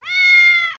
cat03.mp3